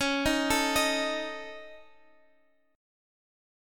C#sus2#5 Chord